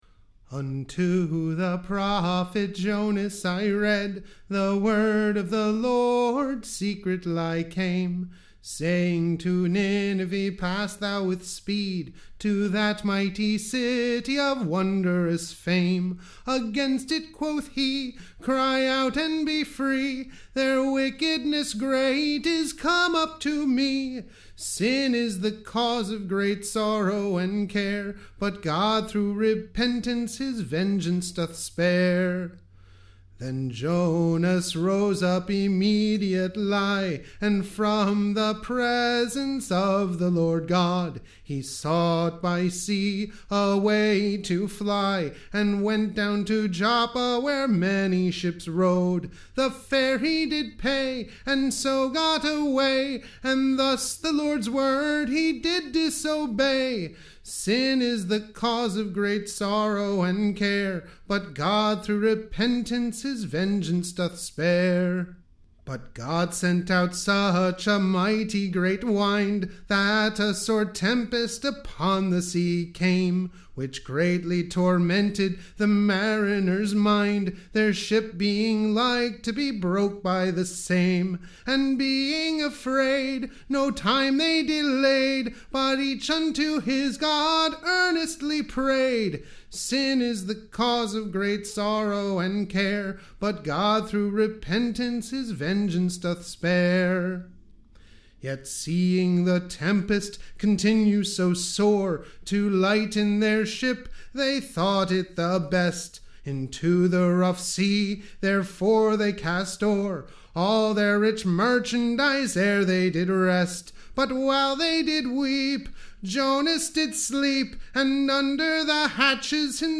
EBBA 20132 - UCSB English Broadside Ballad Archive
Tune Imprint To the tune of Paggintons round.